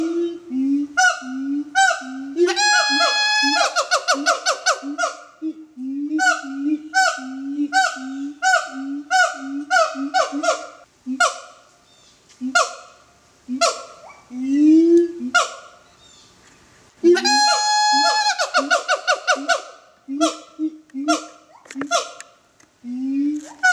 This .wav file is from a Siamang at Zoo MIAMI.